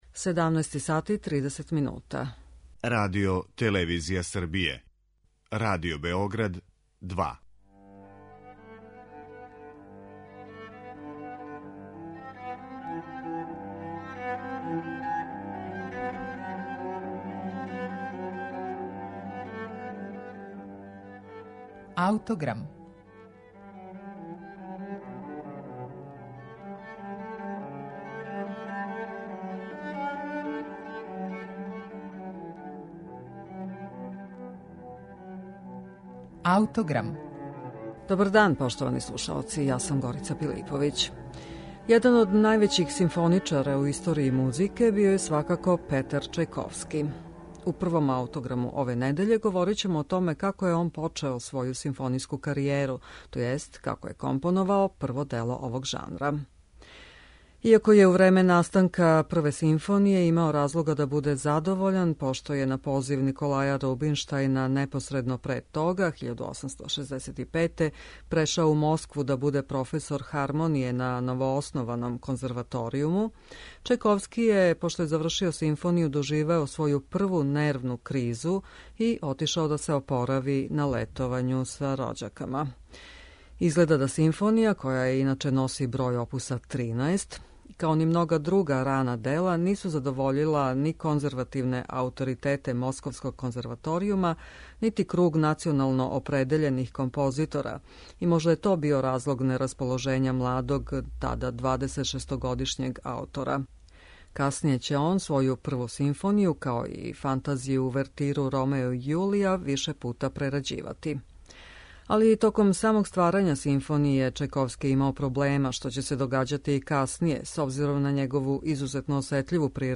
Слушаћемо извођење Гевандхаус оркестра из Лајпцига под управом Дмитрија Китајенка.